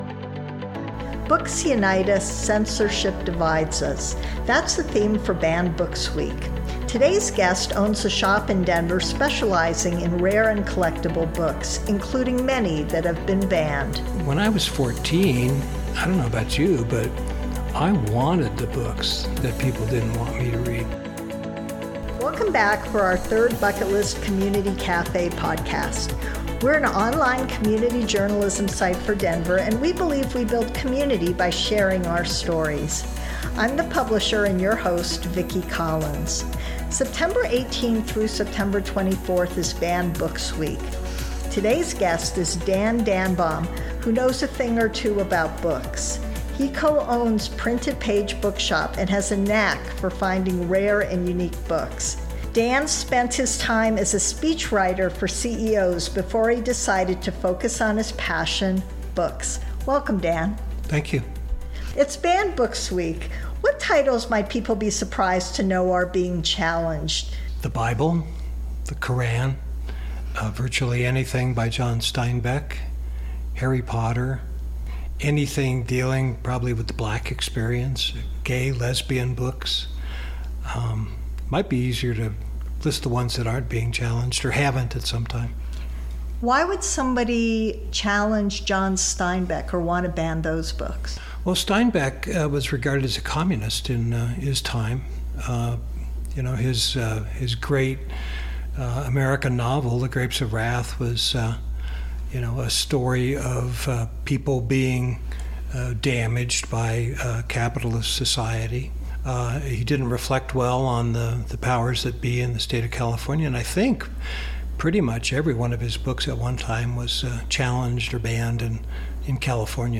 Banned Books Week: a Conversation